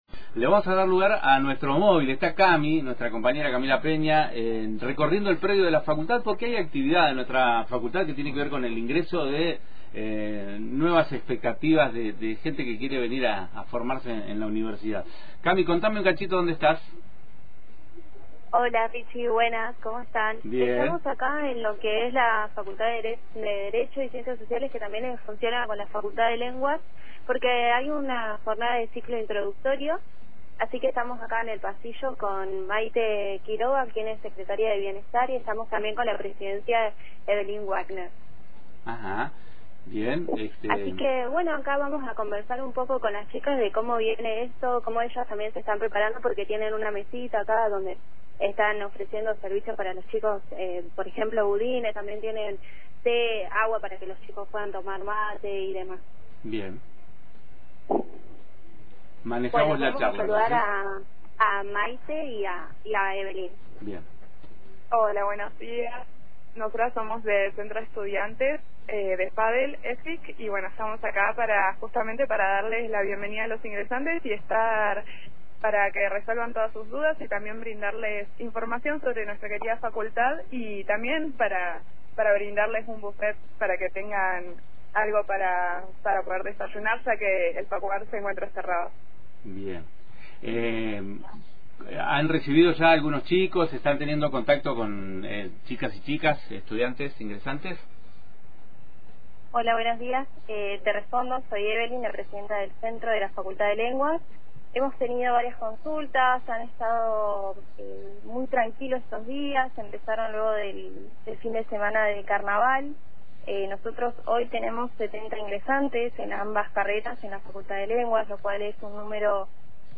En el marco del ciclo introductorio, Antena Libre realizó un móvil desde el predio de la UNCo donde también funciona la Facultad de Lenguas, para cubrir la jornada de bienvenida a ingresantes.